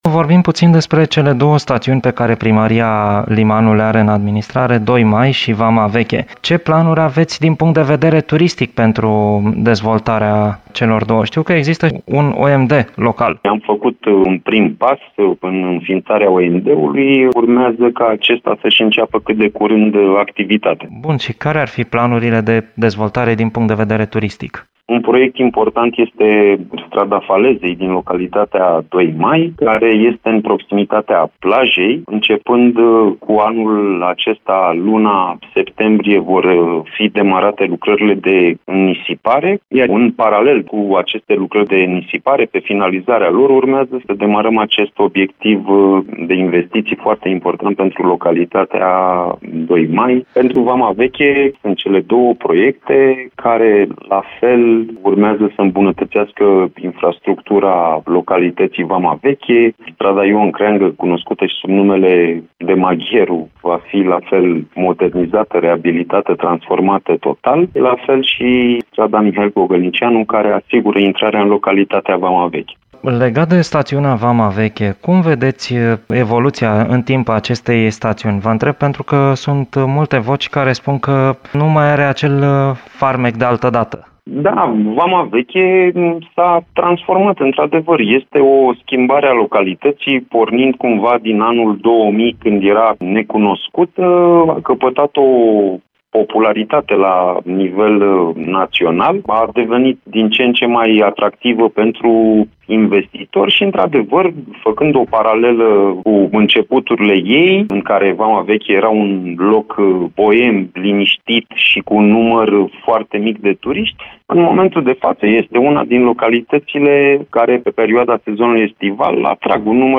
Amănunte aflăm din interviul